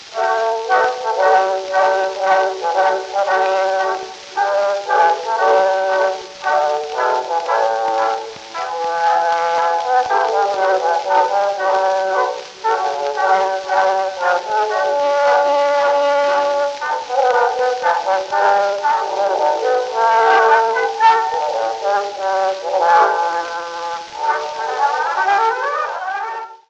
Hier drei Hörbeispiele zum akustischen Verdeutlichen der genannten Zahlenverhältnisse:
Carmen fantaisie, 1899 / 1900. Direktaufnahme im Grand- / Stentor-Format, Ausschnitt.